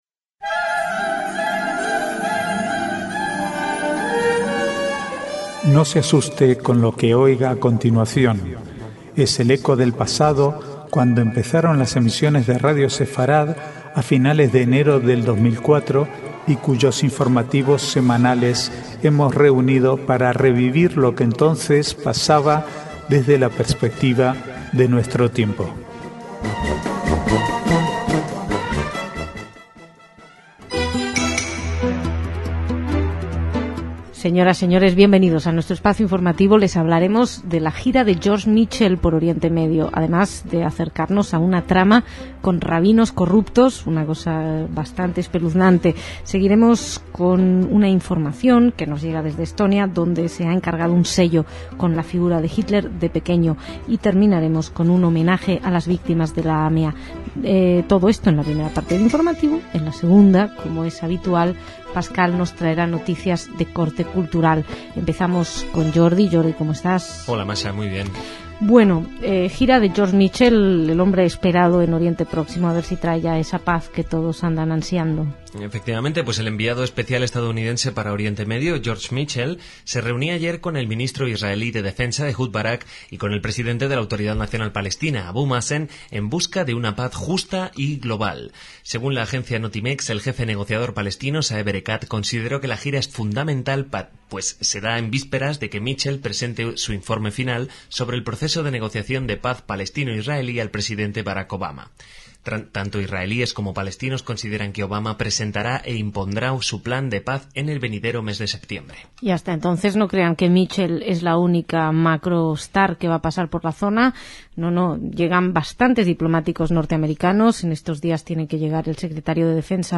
Archivo de noticias del 28 al 30/7/2009